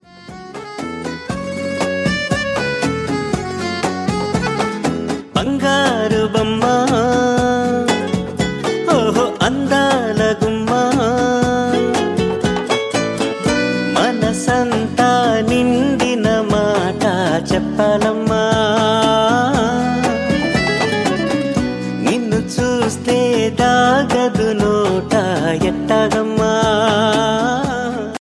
Telugu Ringtonelove ringtonemelody ringtoneromantic ringtone